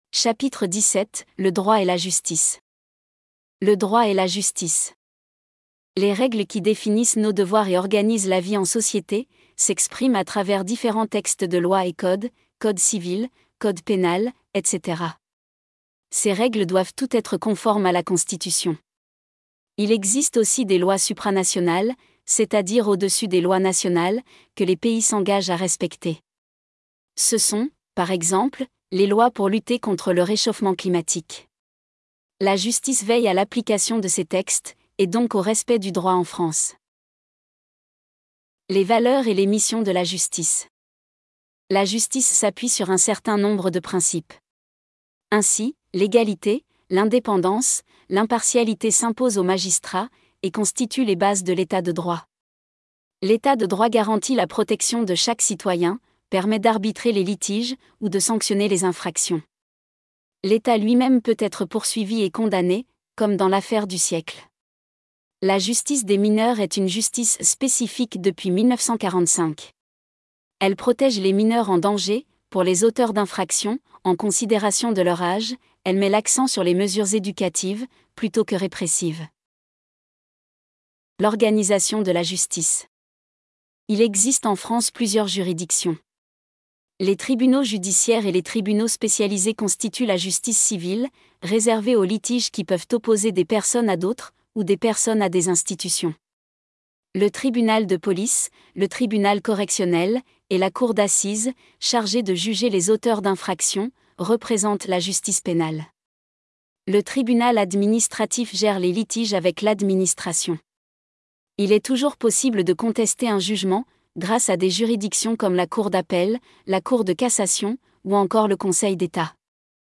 Chap. 17. Cours : Le droit et la justice